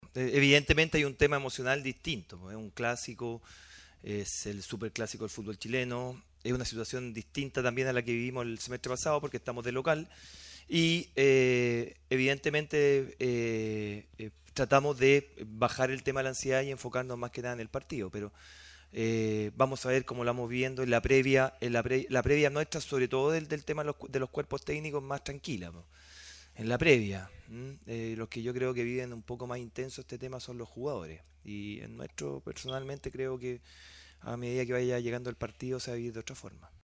El estratega, en conferencia de prensa, destacó que “es el Superclásico del fútbol chileno, es una situación distinta al semestre pasado porque estamos de local, y evidentemente tratamos de bajar la ansiedad y enfocarnos en el partido”.